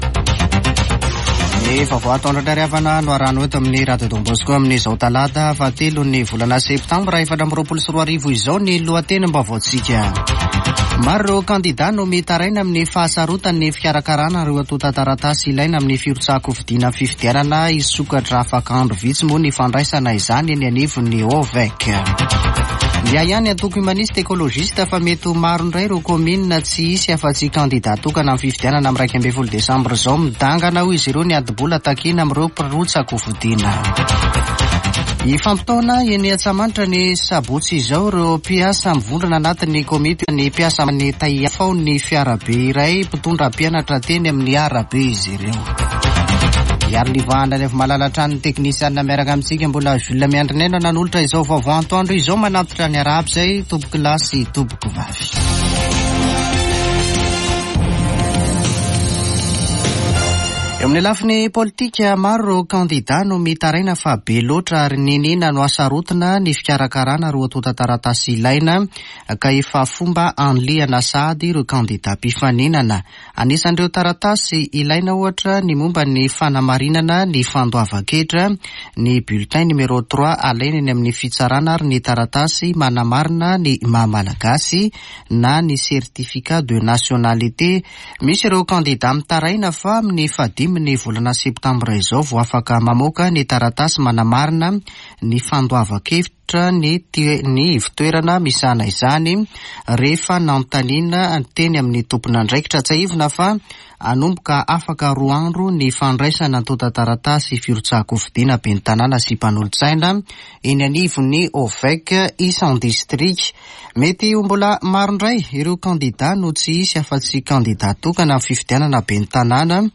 [Vaovao antoandro] Talata 3 septambra 2024